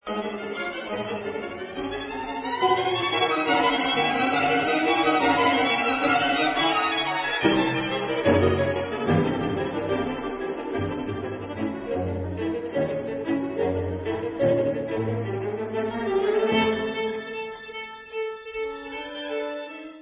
Vif et agité